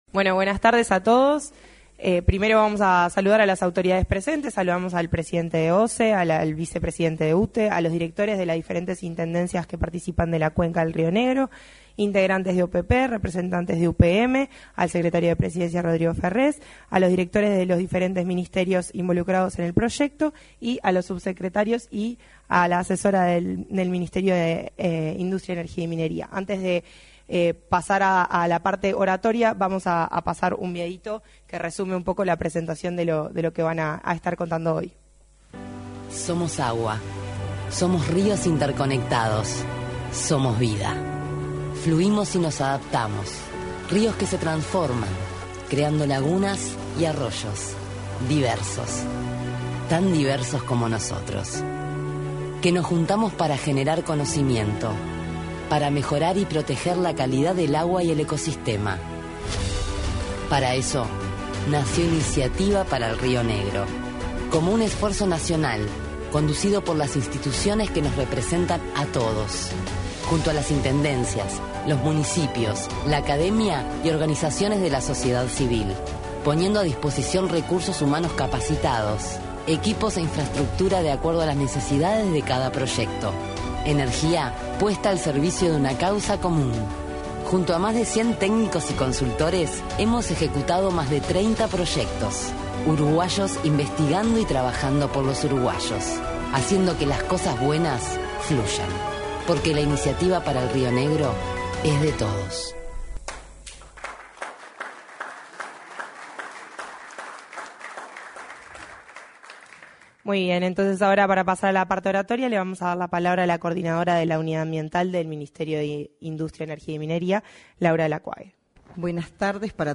Este miércoles 18, se realizó, en el salón de actos de la Torre Ejecutiva, la presentación de resultados del proyecto Iniciativa Río Negro.